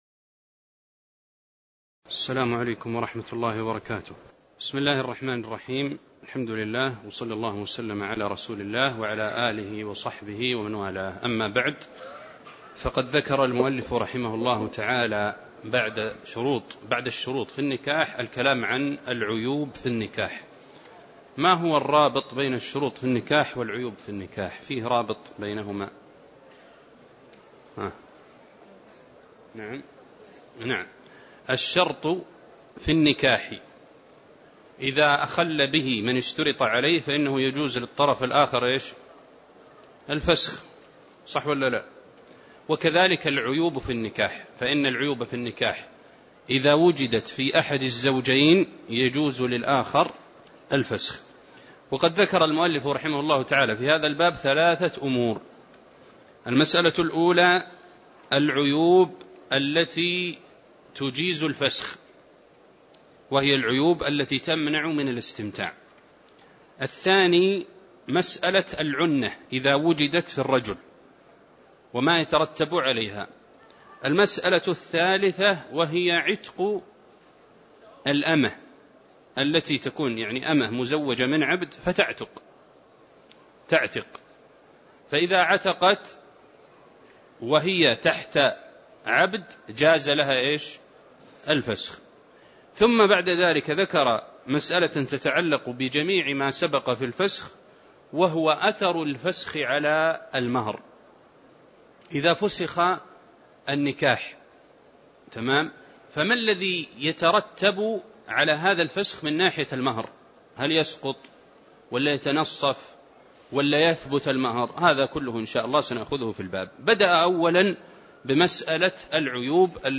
تاريخ النشر ٢٠ شوال ١٤٣٧ هـ المكان: المسجد النبوي الشيخ